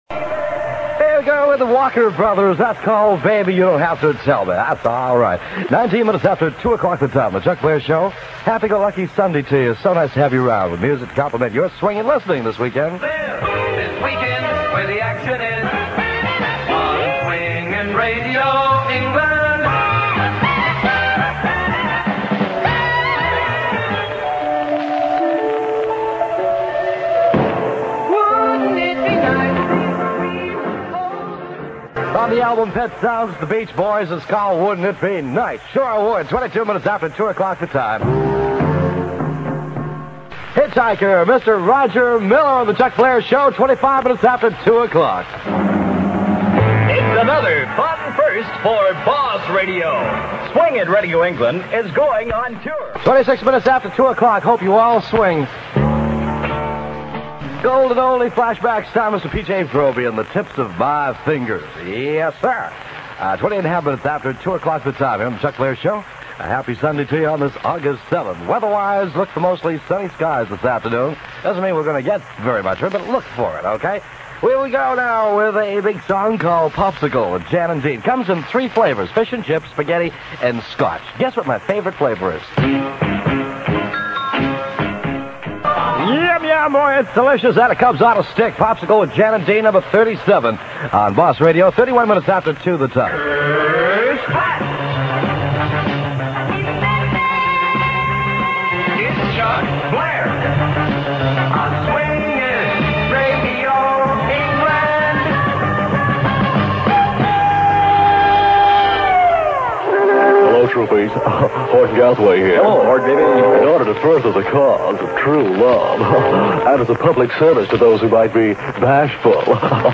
The recording is an extract from the Offshore Echo's tape Offshore Classics vol.27, and is used with kind permission (duration 3 minutes 7 seconds)